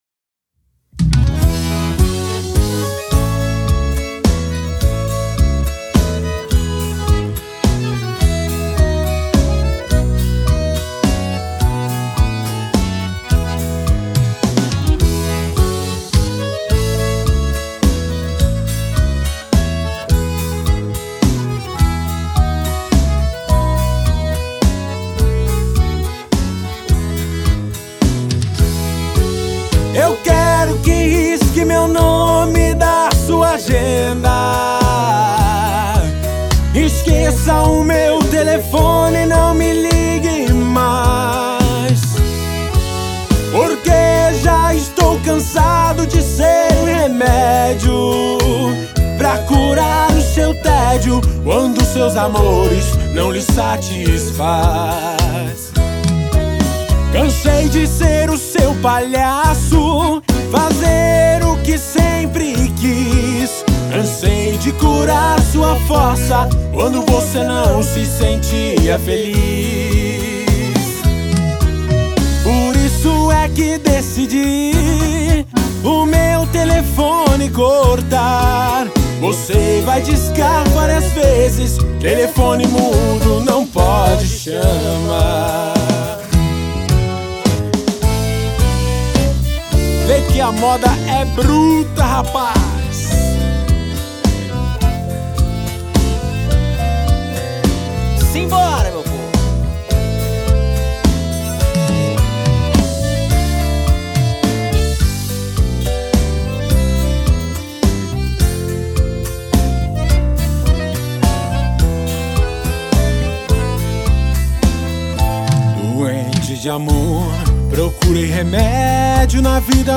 Modão.